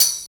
13 TAMB   -L.wav